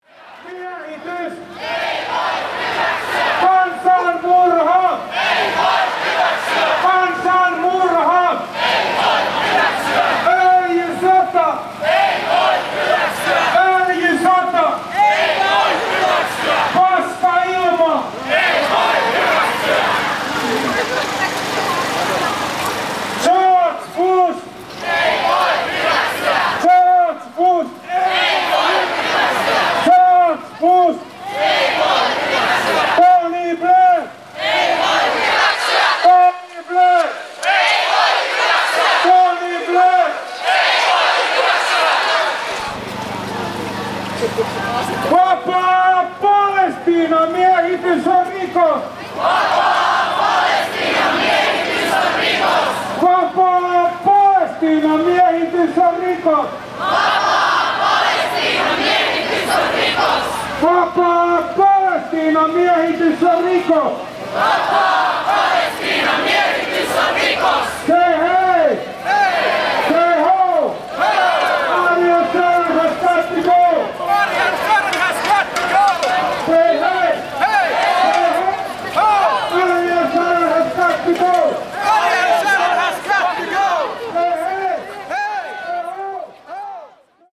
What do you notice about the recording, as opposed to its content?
• place: Helsinki, Finland There is a large peace march snaking through downtown Helsinki on a cold damp morning when I head from the ferry to the workshop.